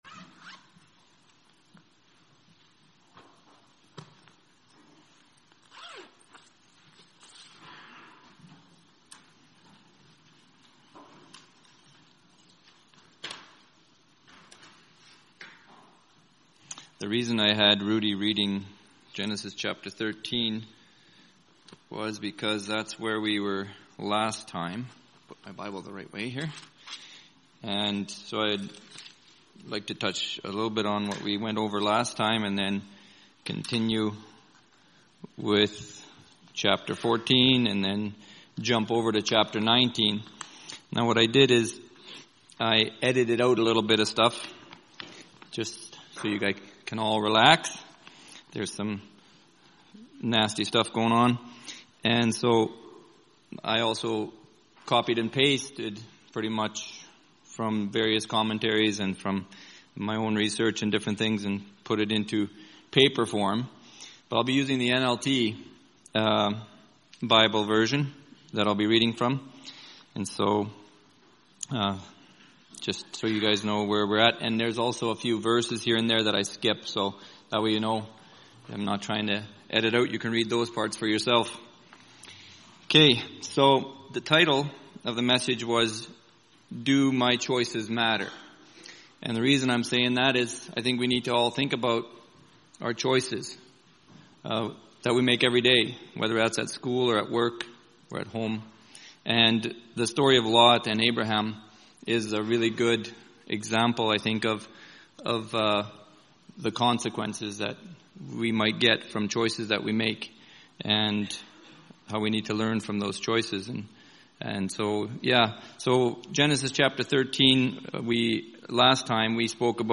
Genesis 14:1-19:38 Service Type: Sunday Morning